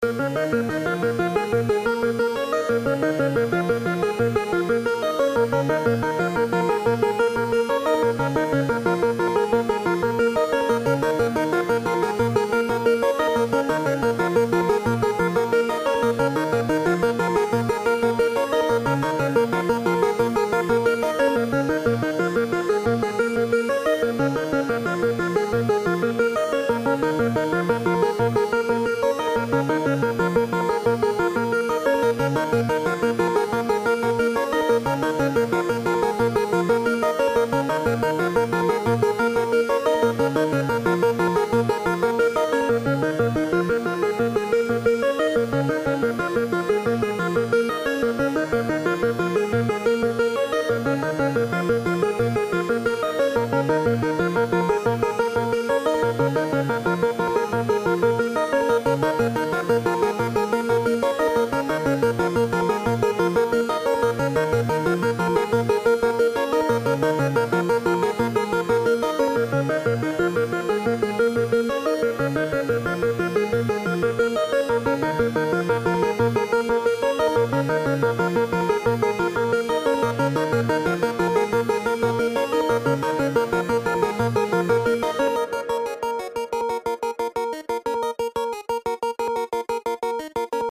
【用途/イメージ】ニュース　メディア　ドキュメント　報道　ナレーション　緊急　事件